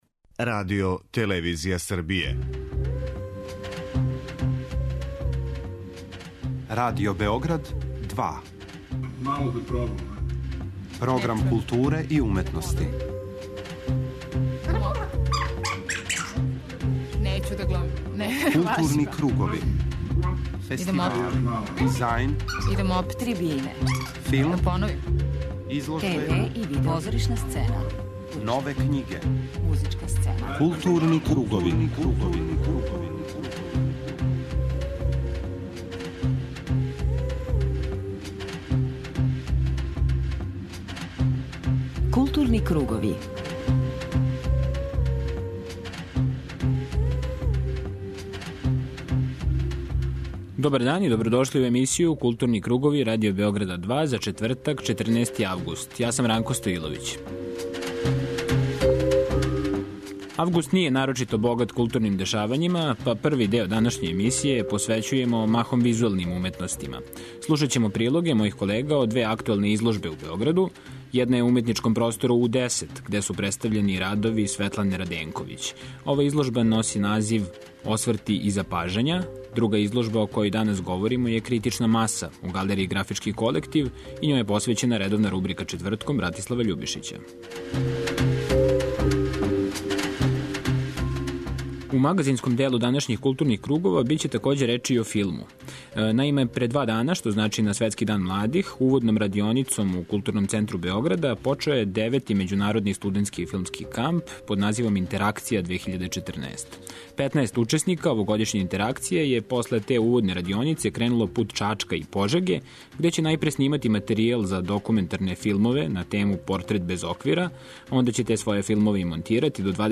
У првом делу емисије информисаћемо вас о актуелним културним дешавањима. У тематском блоку 'Маске' посвећеном позоришту слушаћете два разговора емитована током позоришне сезоне за нама.